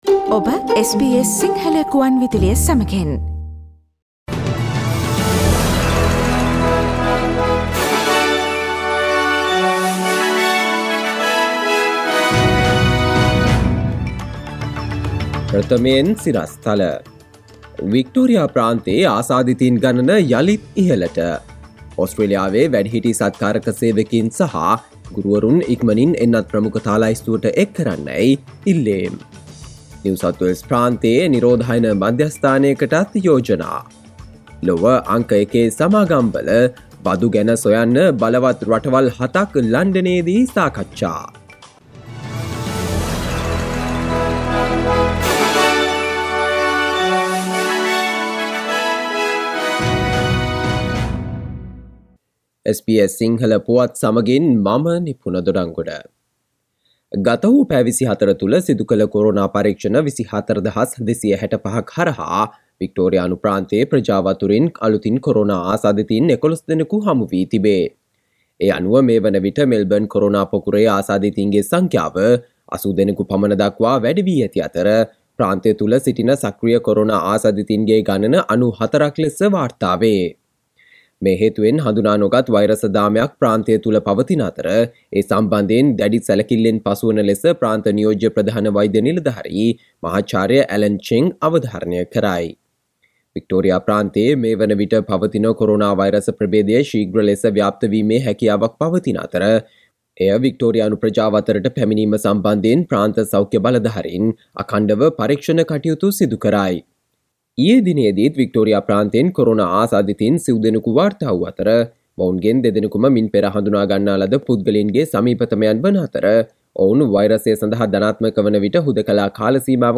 Here are the most prominent Australian and World news highlights from SBS Sinhala radio daily news bulletin on Monday 07 June 2021.